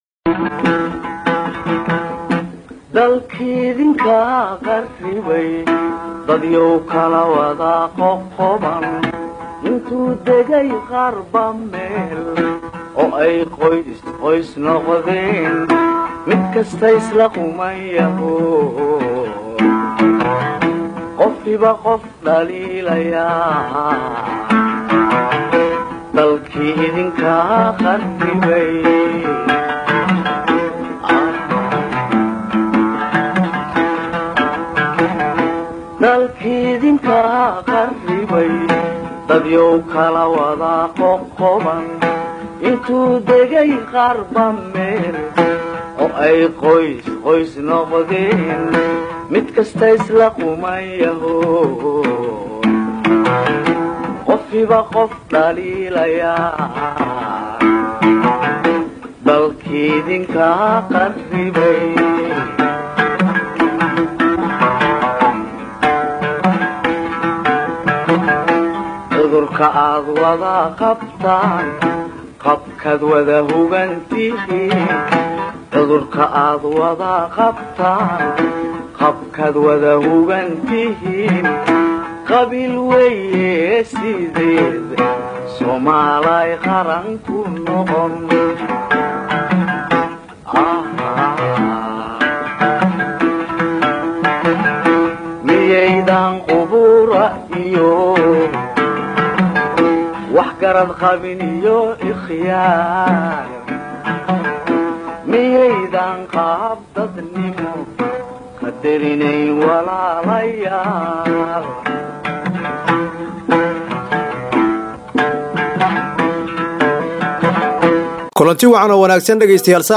Halkan waxaan idiinkugu soogud binaynaa Barnaamijka xulashada waraysiyada Radio cadaado Oo Habeen Walba Idaacada Ka Baxa Marka Laga Reebo Habeenka Jimcaha